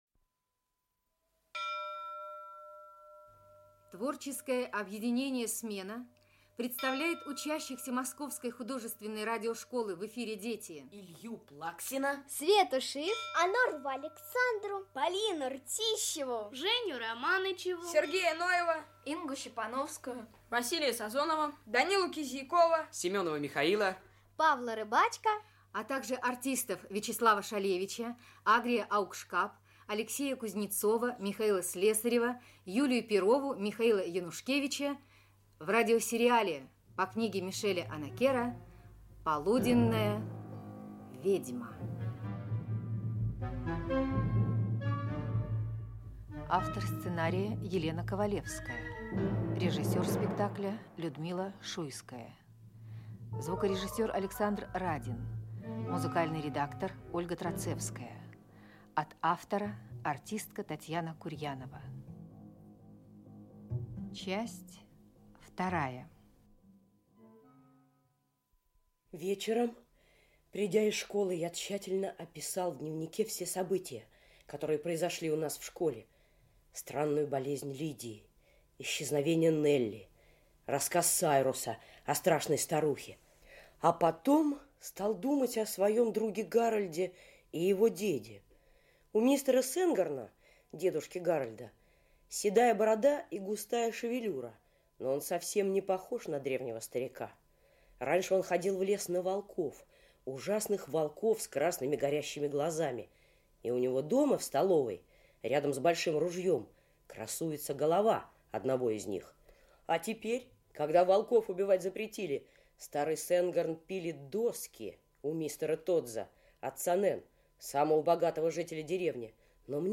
Аудиокнига Полуденная ведьма. Часть 2 | Библиотека аудиокниг
Часть 2 Автор Мишель Онакер Читает аудиокнигу Вячеслав Шалевич.